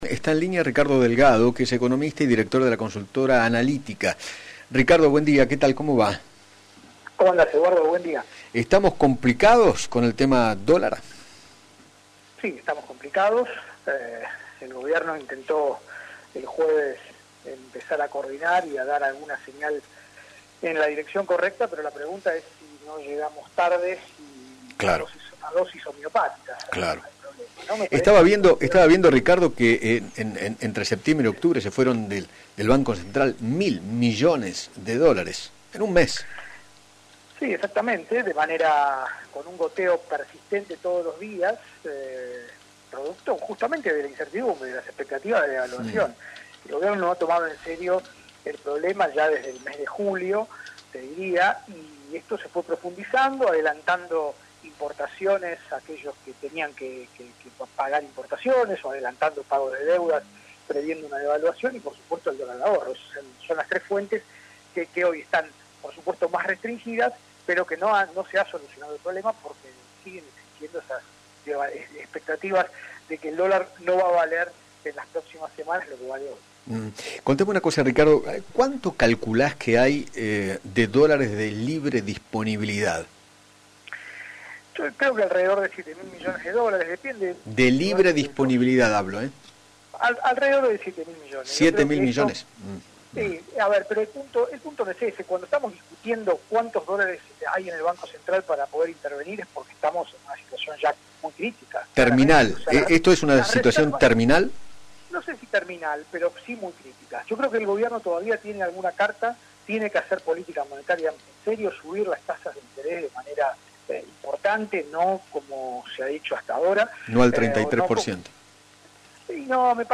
dialogó con Eduardo Feinmann sobre el panorama económico del país y manifestó que “no entiendo por qué no se toman decisiones más profundas de una vez”.